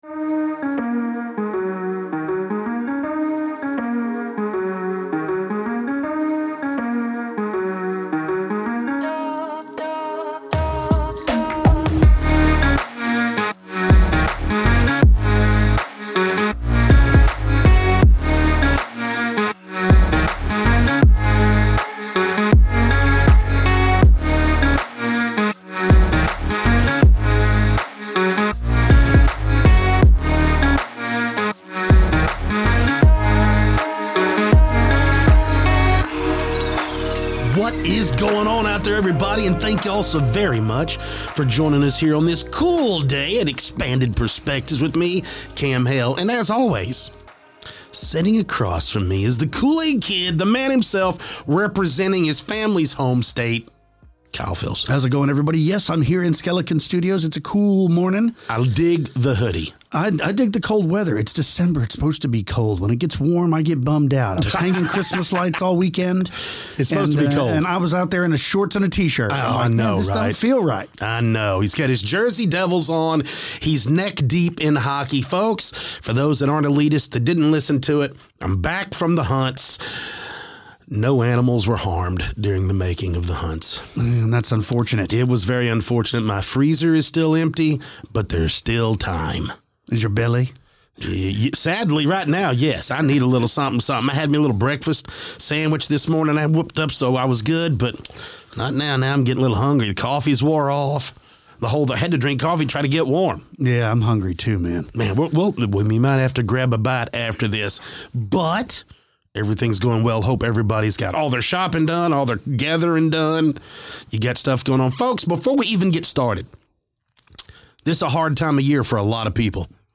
On this episode of Expanded Perspectives, the guys kick things off with a lighthearted conversation that quickly drifts into the strange and unexplained.